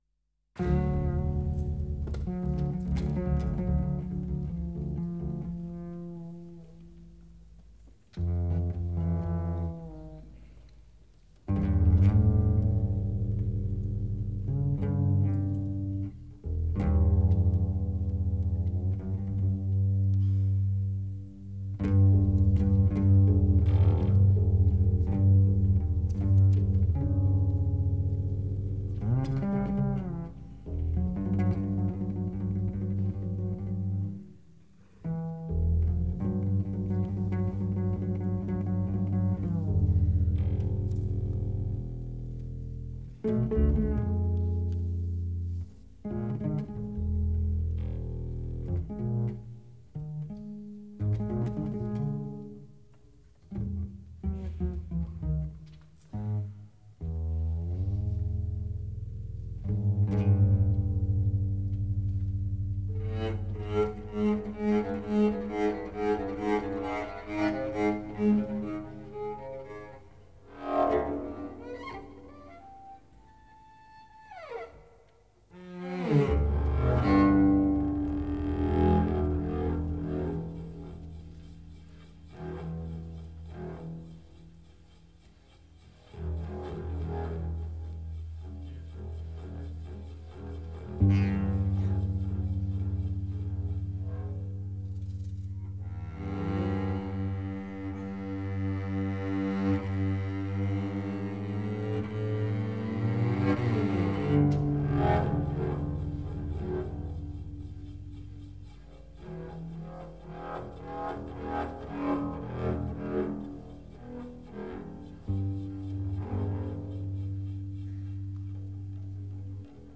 Acoustic Bass